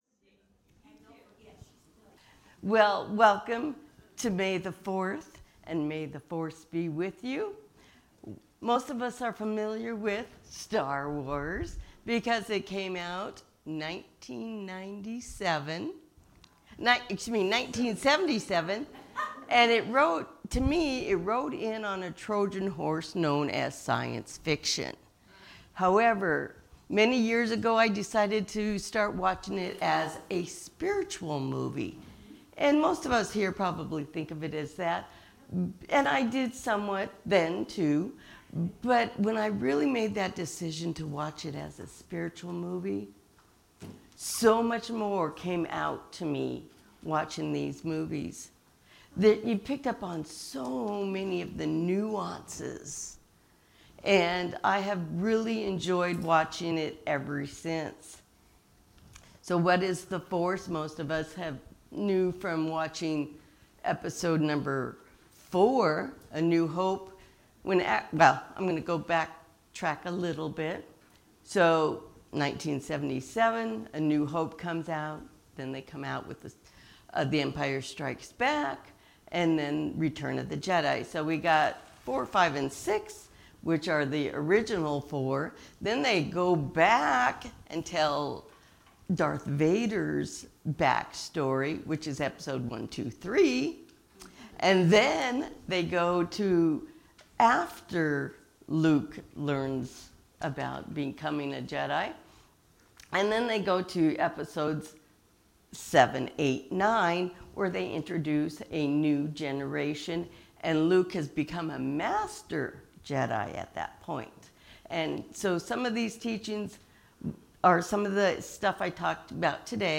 The audio recording (below the video clip) is an abbreviation of the service. It includes the Message and Meditation.